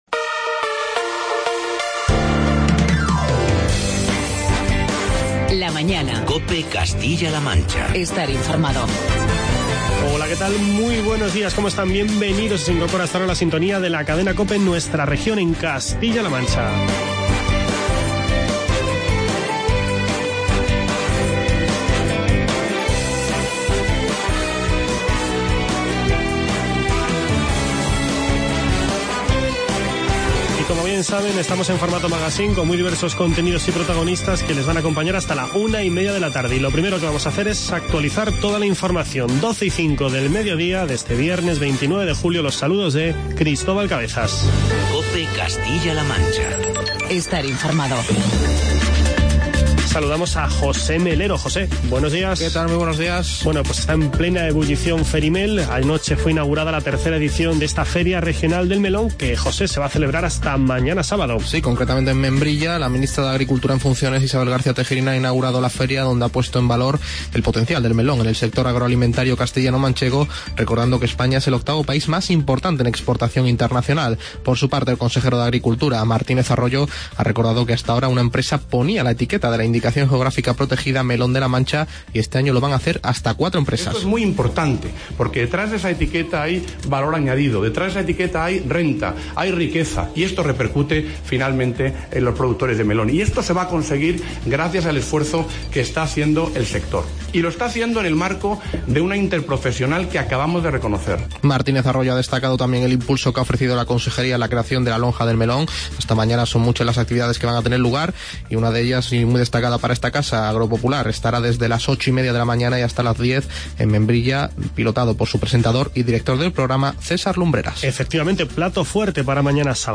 Charlamos con Antonio Lucas-Torres, alcalde de Campo de Criptana.